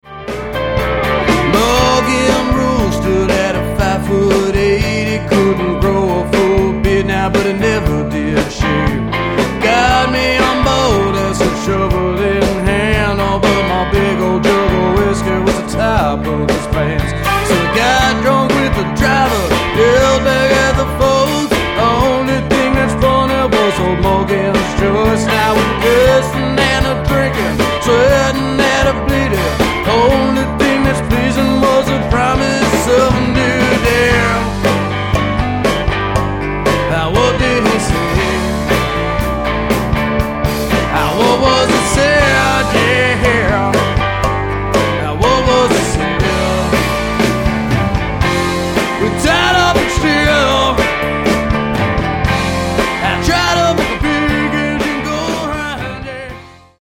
The tracks were recorded primarily "live"
stripped down and hard-rocking.